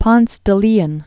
(pŏns də lēən, lē-ōn, pōnthĕ thĕ lĕ-ōn, pōnsĕ), Juan 1460-1521.